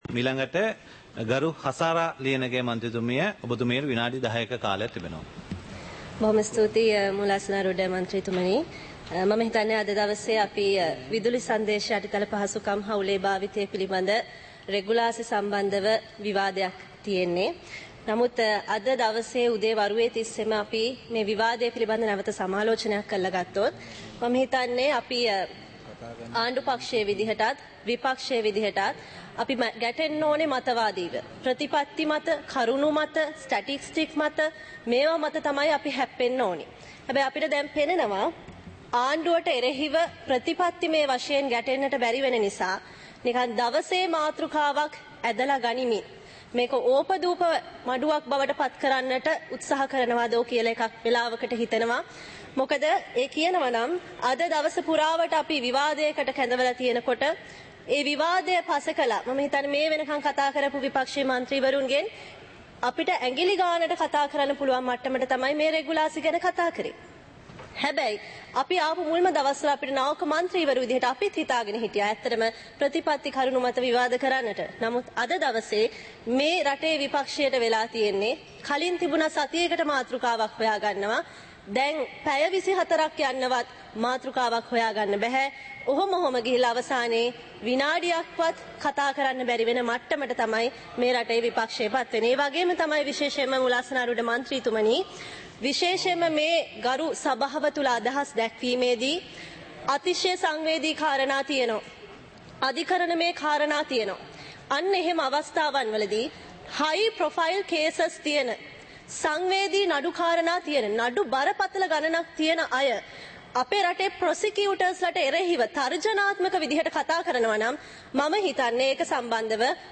Proceedings of the House (2026-02-03)
Parliament Live - Recorded